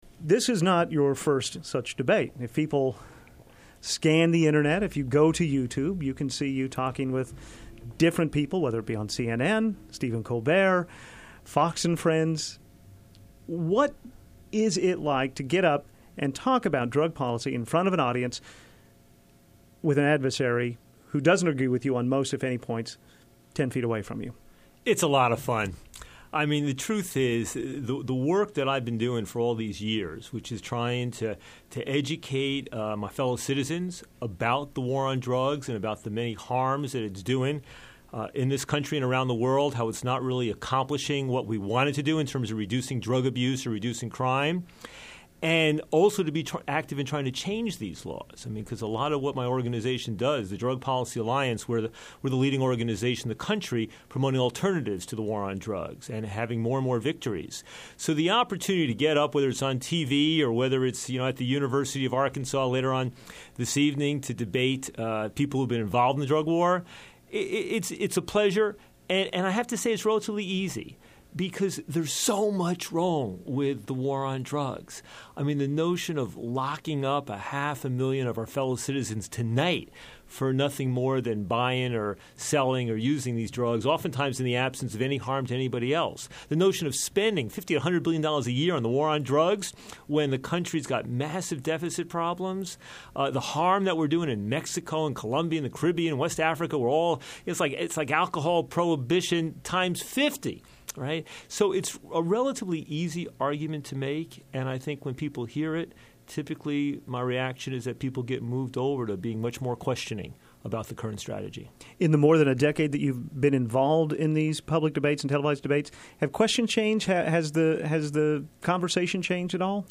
Drug_Policy_Debate.mp3